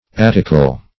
attical - definition of attical - synonyms, pronunciation, spelling from Free Dictionary Search Result for " attical" : The Collaborative International Dictionary of English v.0.48: Attical \At"tic*al\, a. Attic.